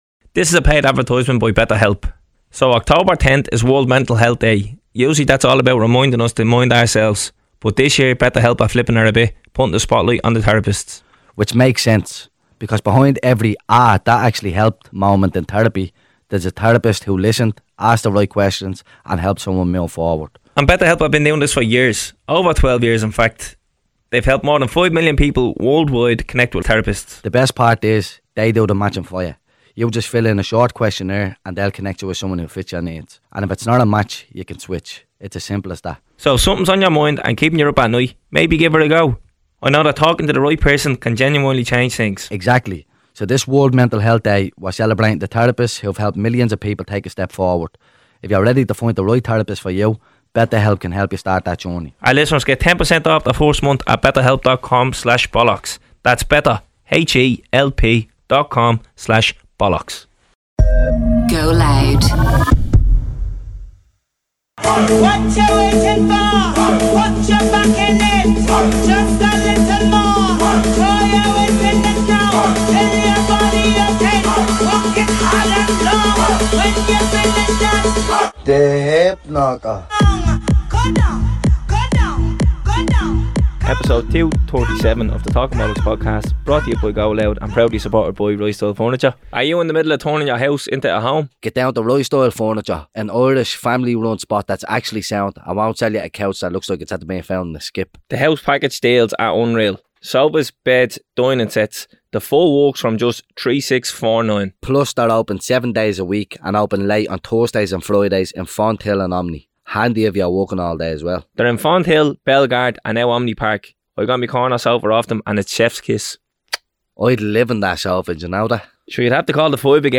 She's incredibly inspiring and we think you'll take a lot from this interview.